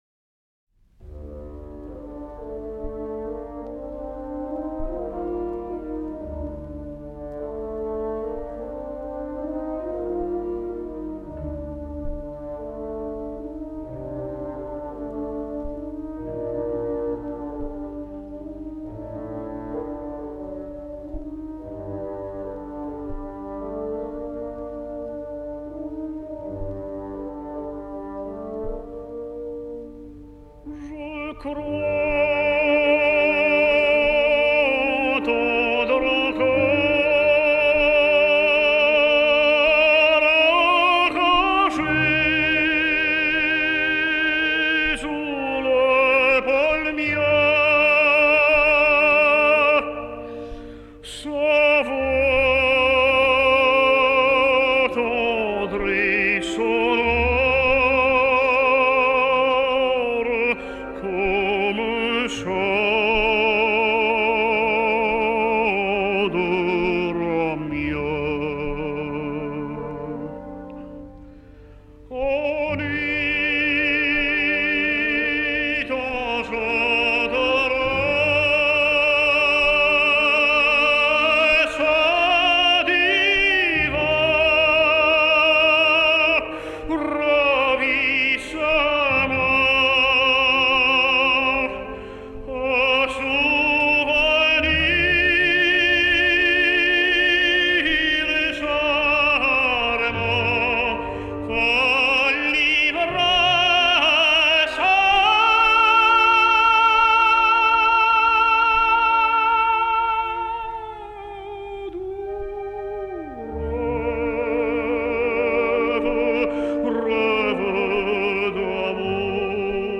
Великолепный тенор!